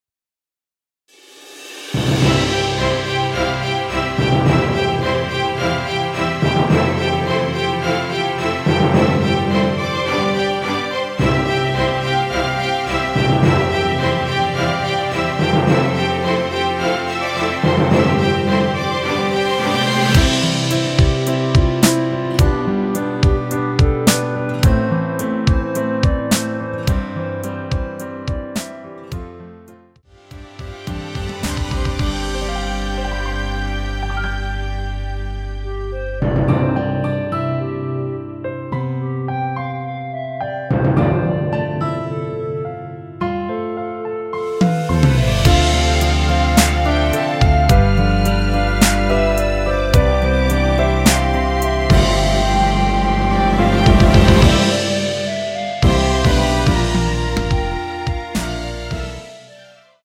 원키 멜로디 포함된 MR입니다.(미리듣기 확인)
멜로디 MR이라고 합니다.
앞부분30초, 뒷부분30초씩 편집해서 올려 드리고 있습니다.
중간에 음이 끈어지고 다시 나오는 이유는